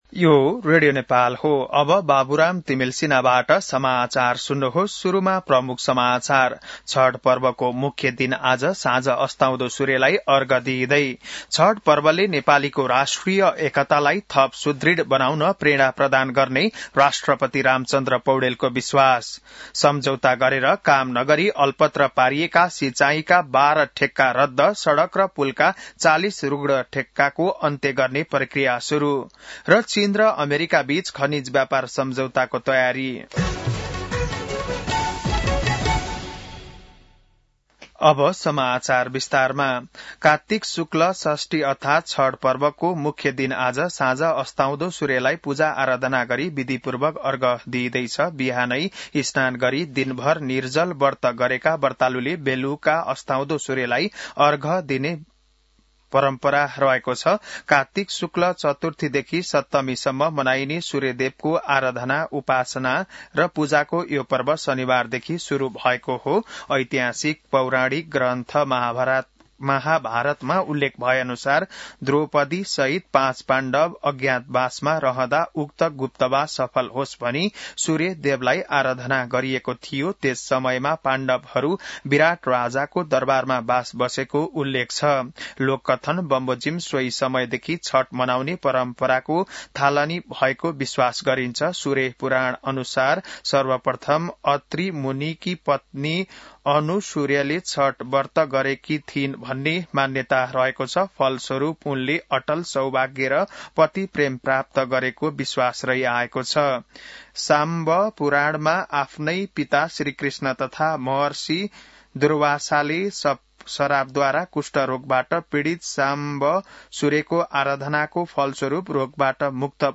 बिहान ९ बजेको नेपाली समाचार : १० कार्तिक , २०८२